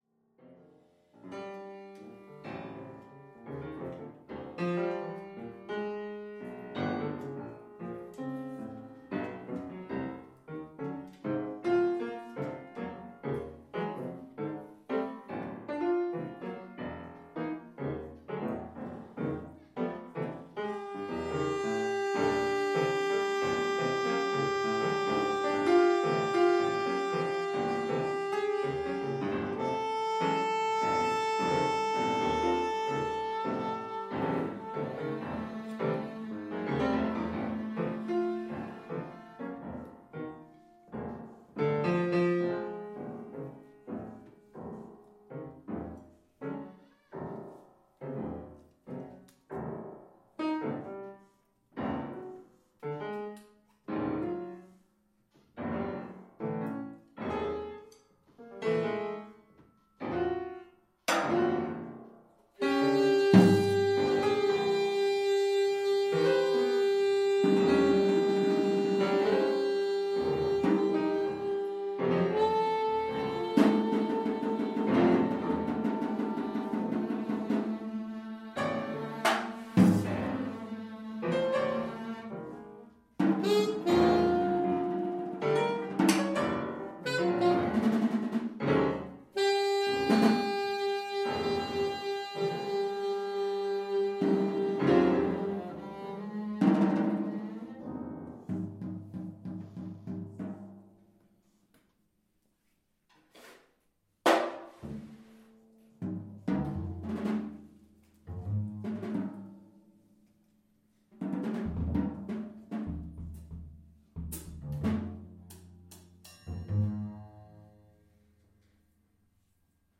Live at the Firehouse Space Brooklyn, New York 10/02/16
saxophones
piano
bass
drums